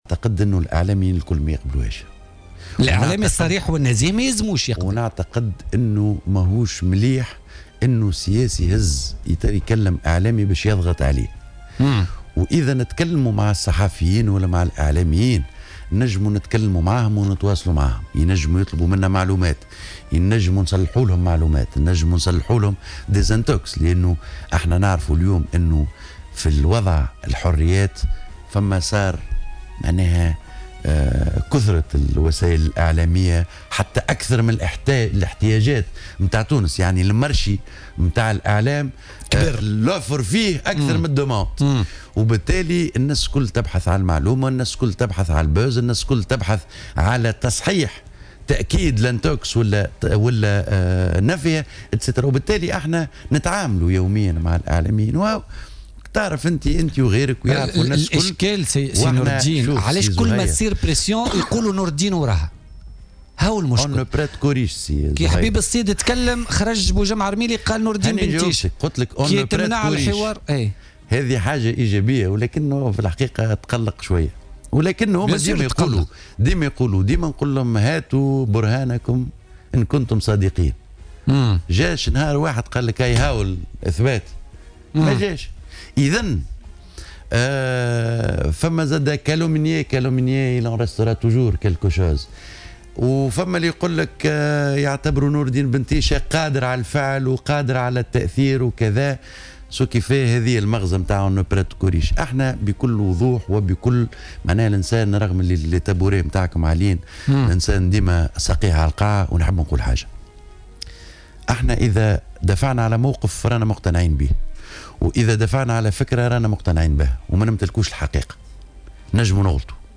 نفى نور الدين بن تيشة، المستشار لدى رئيس الجمهورية في مداخلة له اليوم في برنامج "بوليتيكا" كل الاتهامات التي وجهت له بخصوص ممارسته لضغوطات على عدد من الإعلاميين.